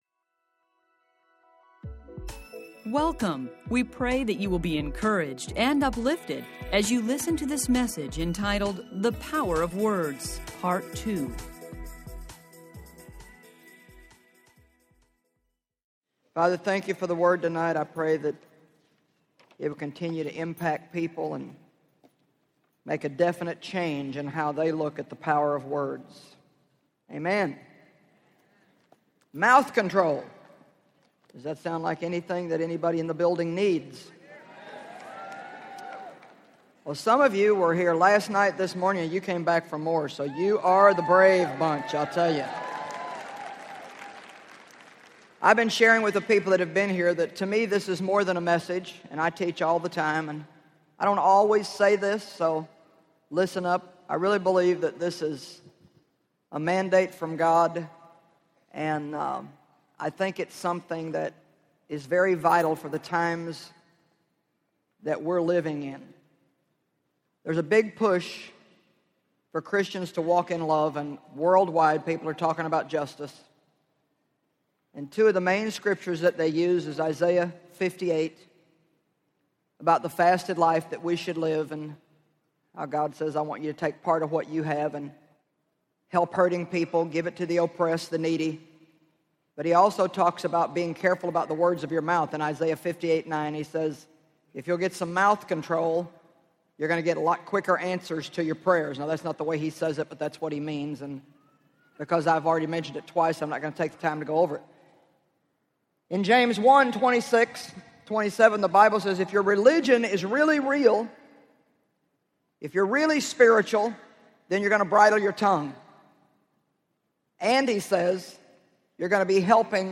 Narrator
3.87 Hrs. – Unabridged